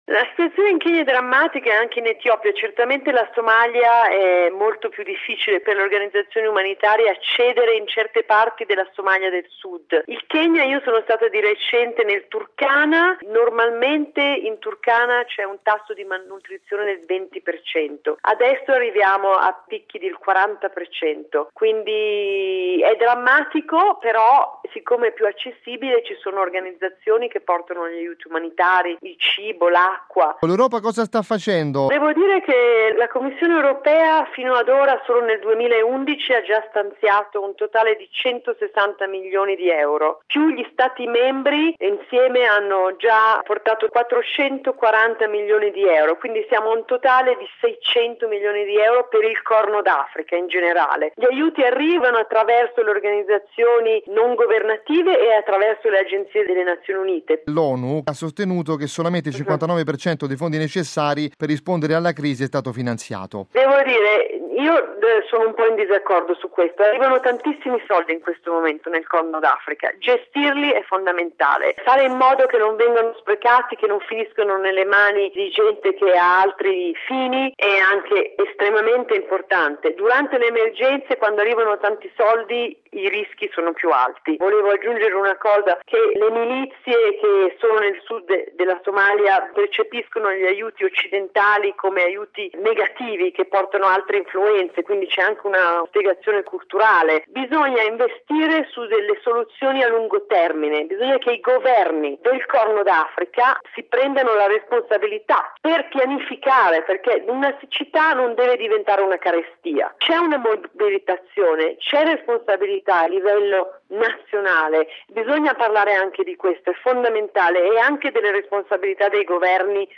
raggiunta telefonicamente a Nairobi: